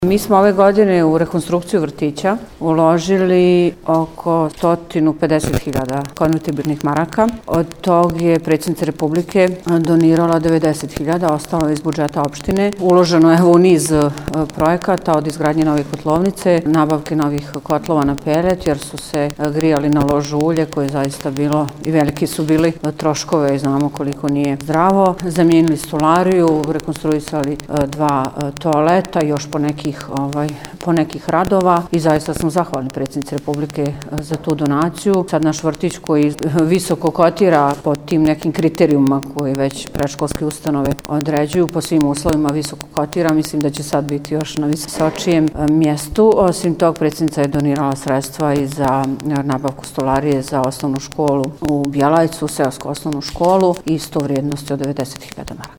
izjavu
DIVNA-ANICIC-NACELNIK-VRTIC.mp3